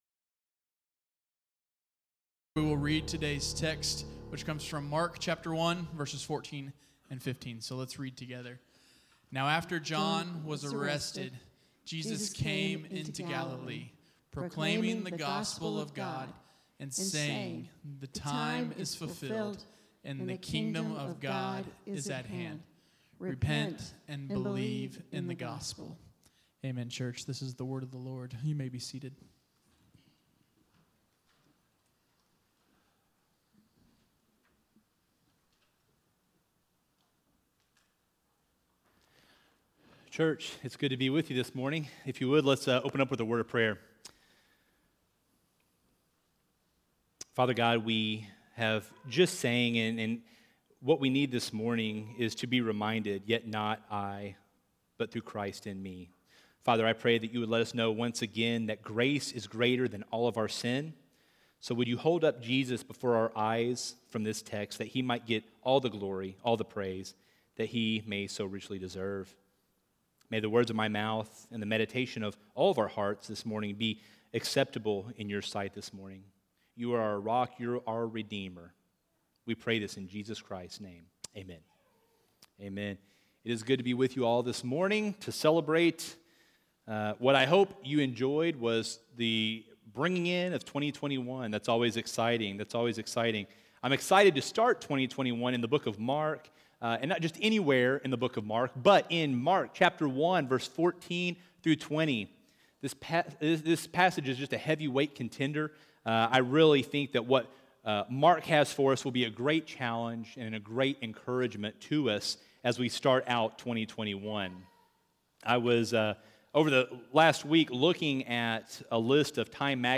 A message from the series "Mark."